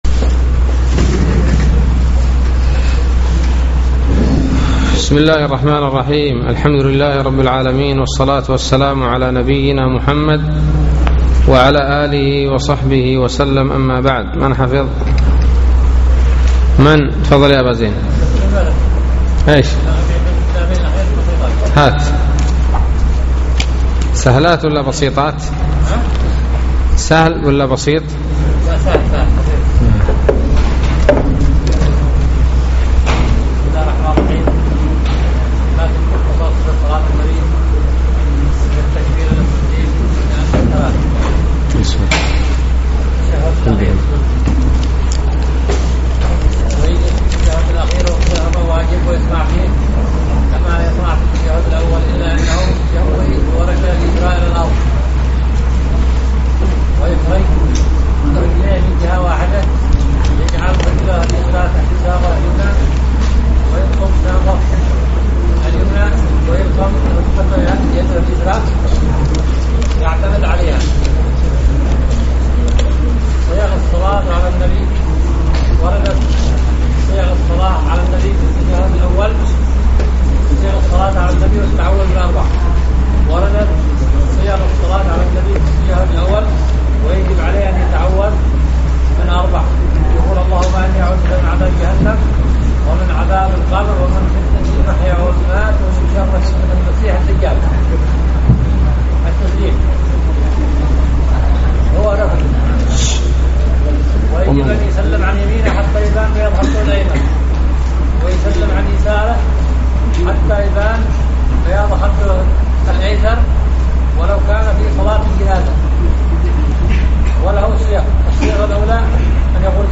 الدرس السابع والثلاثون من شرح كتاب التوحيد